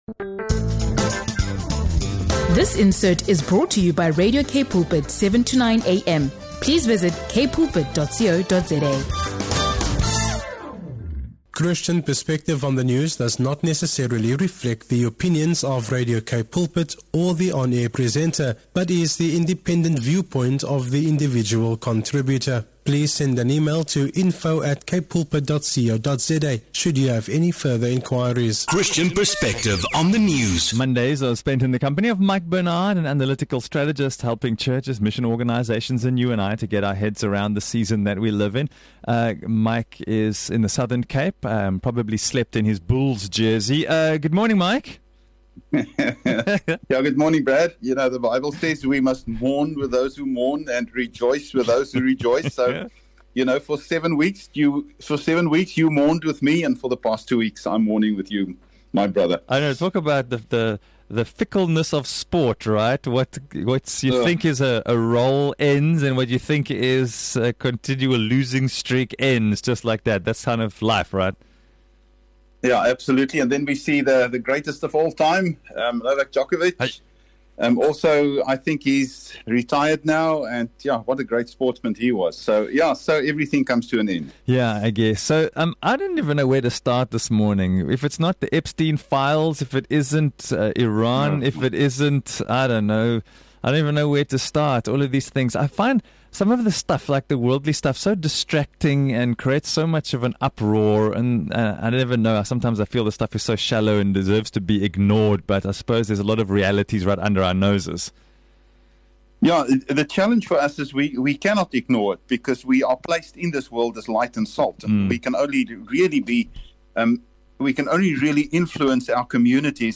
The conversation explores how misinformation spreads, how easily believers can be drawn into conflict, and why unity in Christ must remain central to our witness.